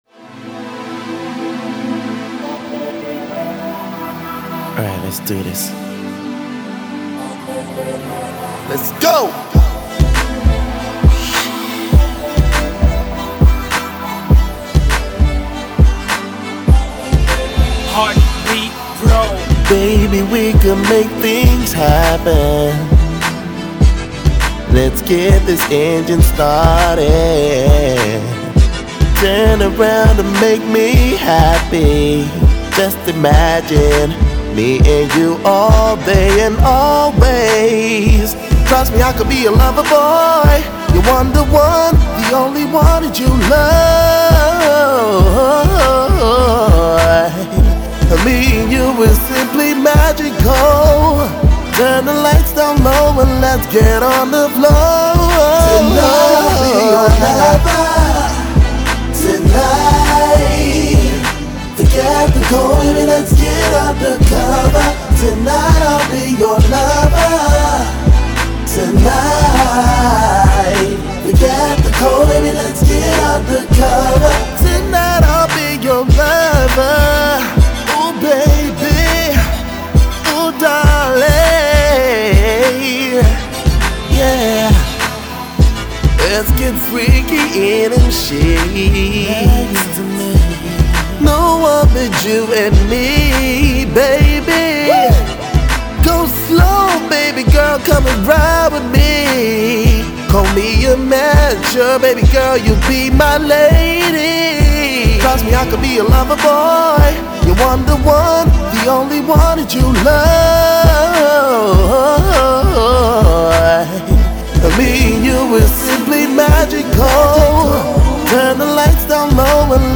contemporary R&B-flavoured joint